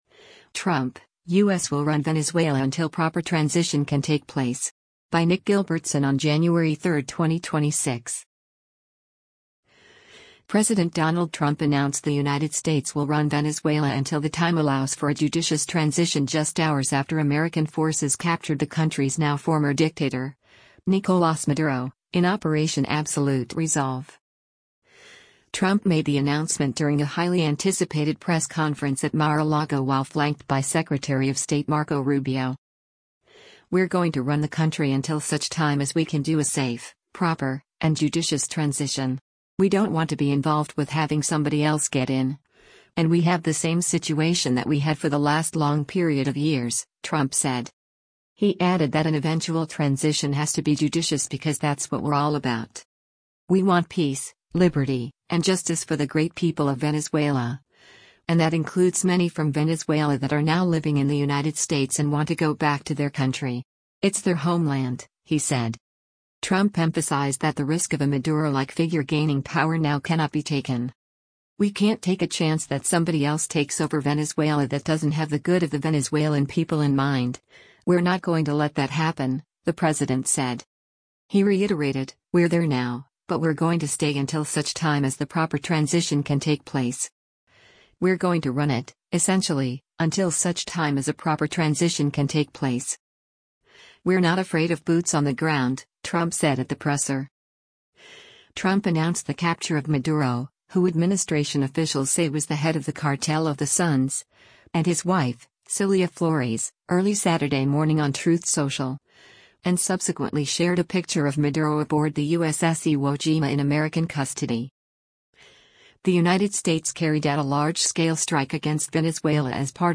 Donald Trump announces operation in Venezuela
Trump made the announcement during a highly anticipated press conference at Mar-a-Lago while flanked by Secretary of State Marco Rubio.